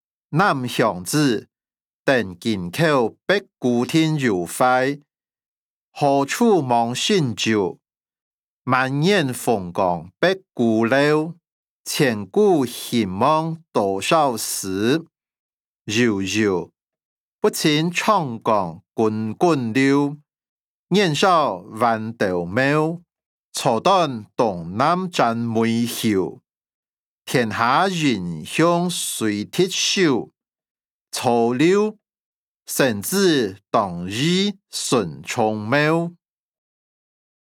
詞、曲-南鄉子•登京口北固亭有懷音檔(饒平腔)